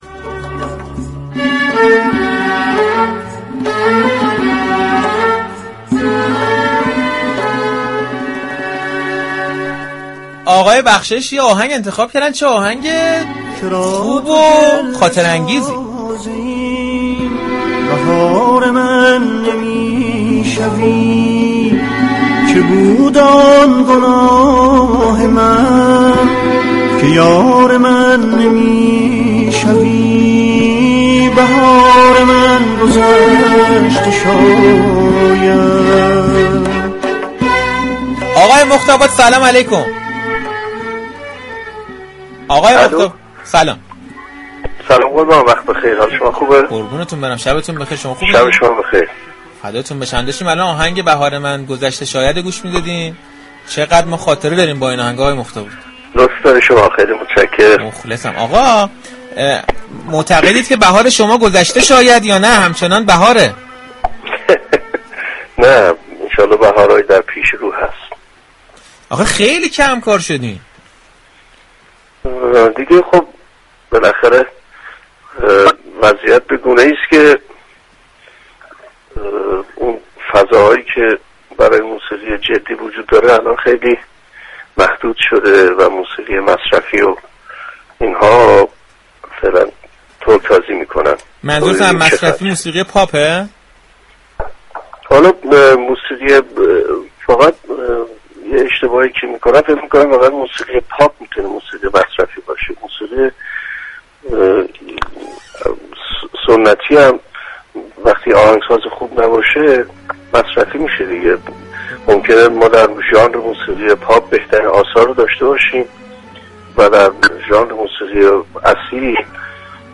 سید عبدالحسین مختاباد، آهنگ‌ساز و خواننده‌ی كشورمان در گفتگو با برنامه صحنه‌ی رادیو تهران 31 فروردین برای مخاطبان این رسانه از علت كم‌كار بودنش در عرصه‌ی هنر موسیقی گفت.